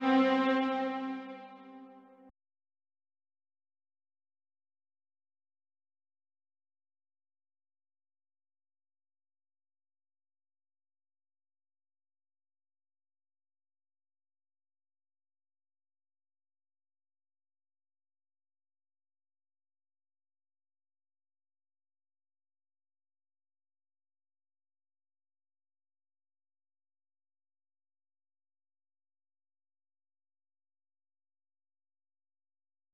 Short Strings